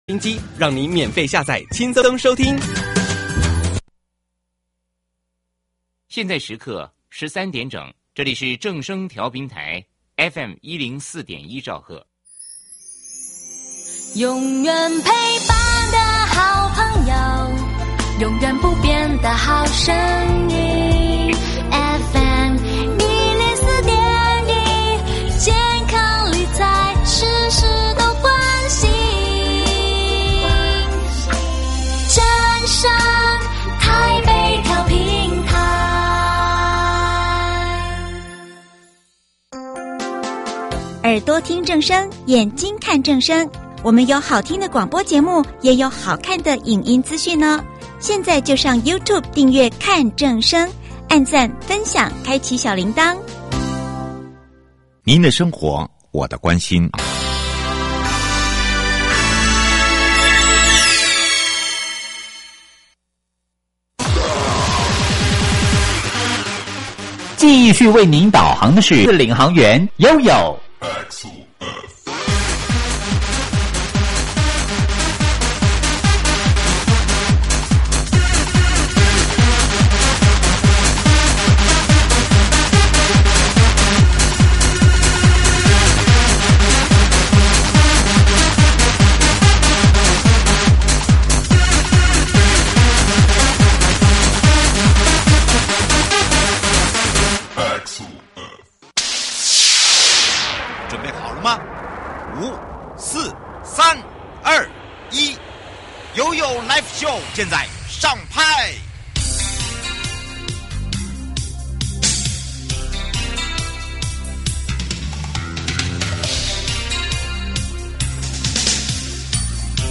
受訪者： 營建你我他 快樂平安行~七嘴八舌講清楚~樂活街道自在同行!(五) 行人優先、友善共行——桃園市92處